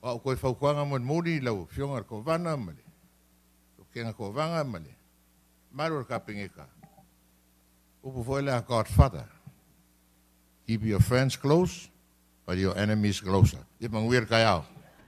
Senate President Tuaolo Manaia Fruean said at the opening of the special session of the 38th Legislature this morning that the Fono will accept the Governor’s request to approve the $36 million supplemental appropriations for Fiscal Year 2023.
The Senate President finished with this advice.